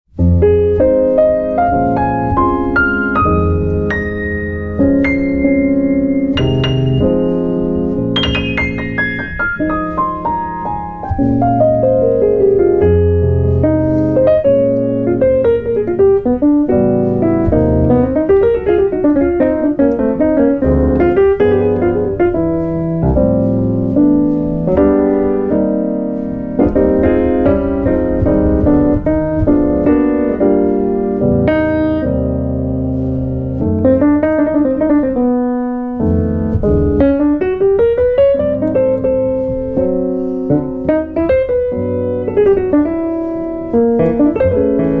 Piano
Guitar